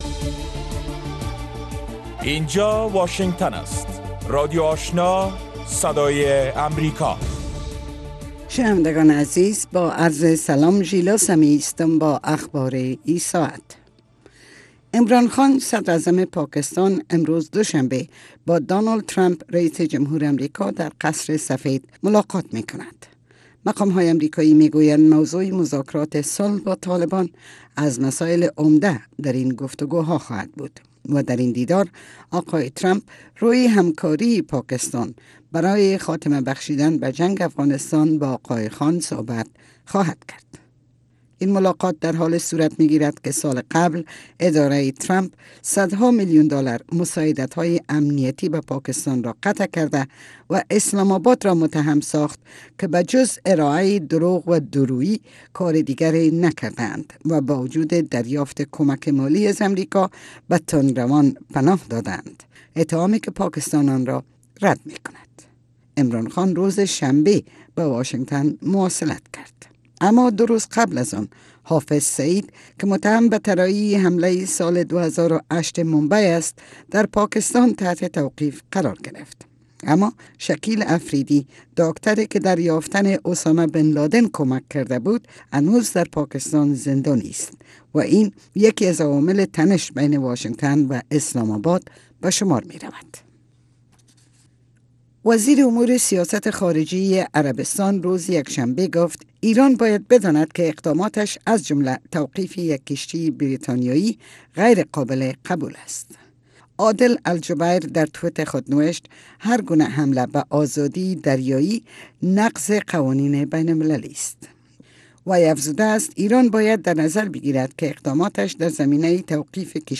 در برنامۀ صبحگاهی خبرهای تازه از افغانستان و جهان و گزارش‌های تحلیلی و مصاحبه ها در پیوند با رویدادهای داغ افغانستان و جهان به شما پیشکش می شود.